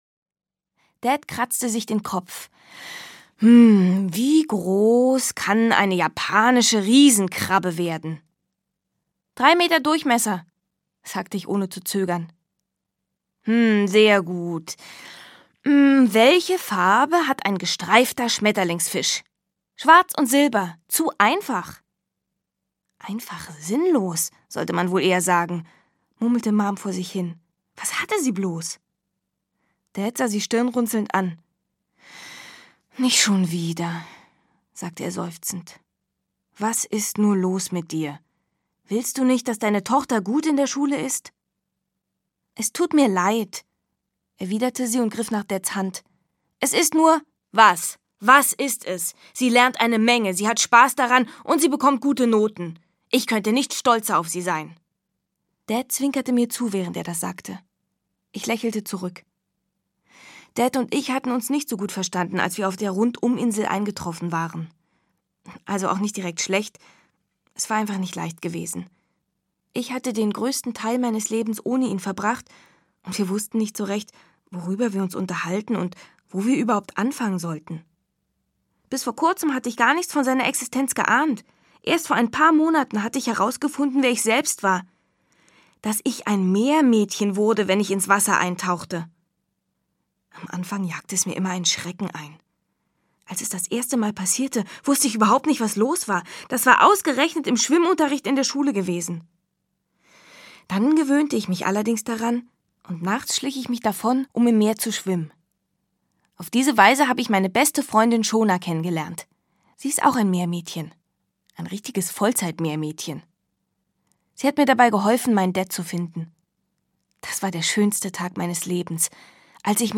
Emilys Entdeckung - Liz Kessler - Hörbuch